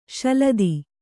♪ śaladi